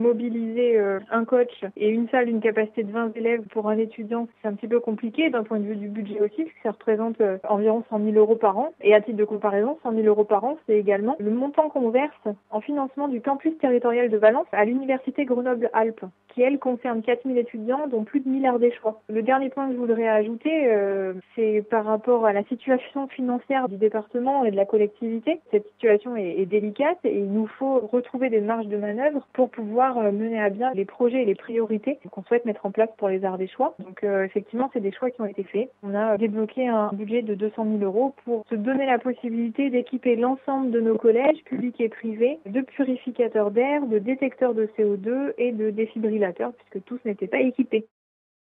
Contacté par téléphone, la vice-présidente du Conseil départemental en charge de l’éducation et de la jeunesse évoque d’abord des effectifs insuffisants.
Ingrid Richioud, vice-présidente du Conseil départemental de l’Ardèche, en charge de l’éducation et de la jeunesse.